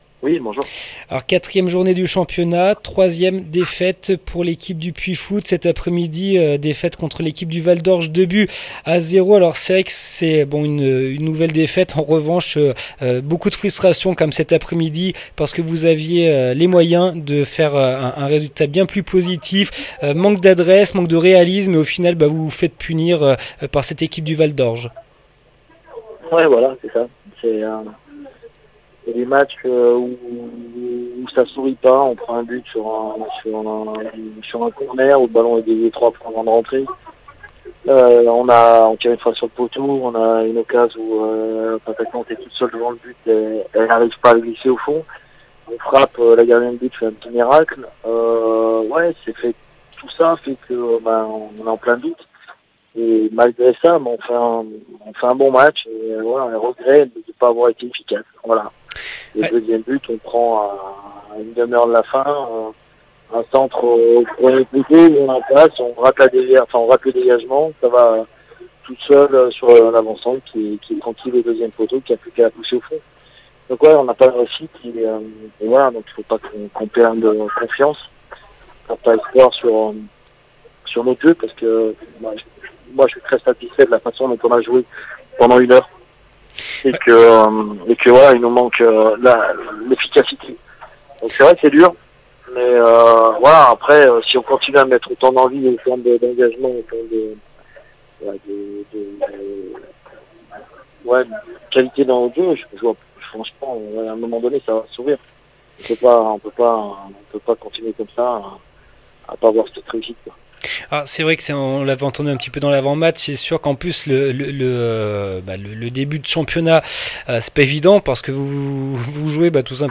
3 octobre 2016   1 - Sport, 1 - Vos interviews, 2 - Infos en Bref   No comments